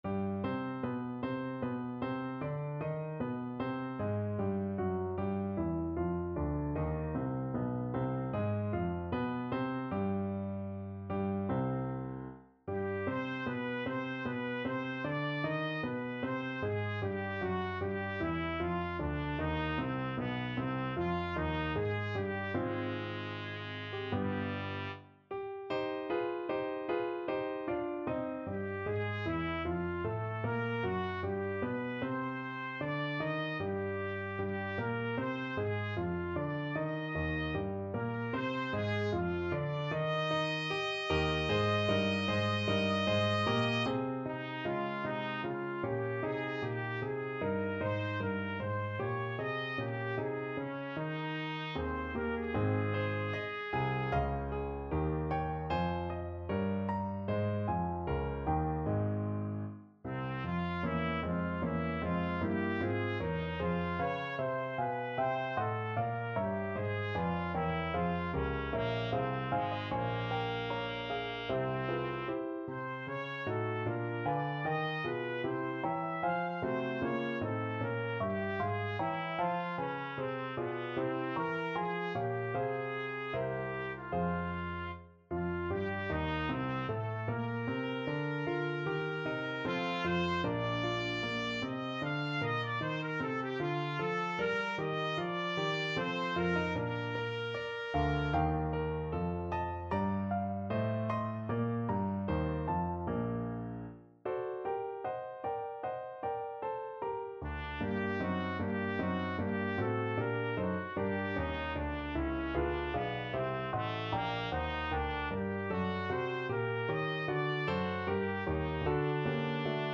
Trumpet
C minor (Sounding Pitch) D minor (Trumpet in Bb) (View more C minor Music for Trumpet )
4/4 (View more 4/4 Music)
Larghetto (=76)
Classical (View more Classical Trumpet Music)